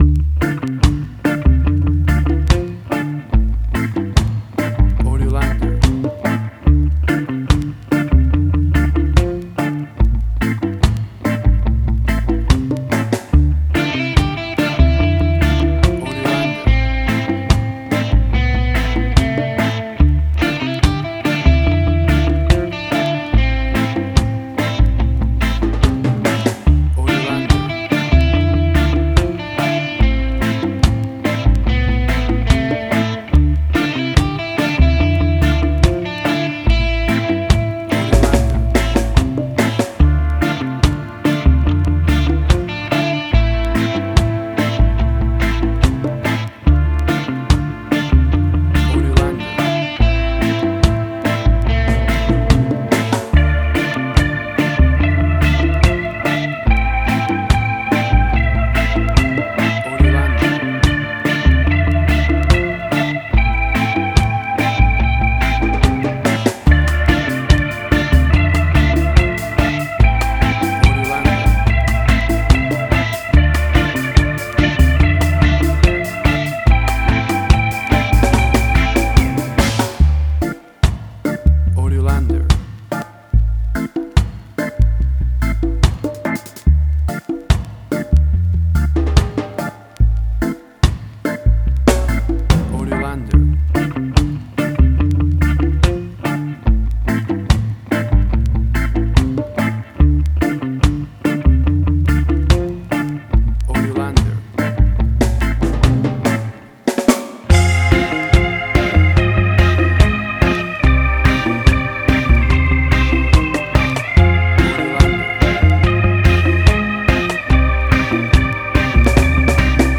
Reggae caribbean Dub Roots
Tempo (BPM): 72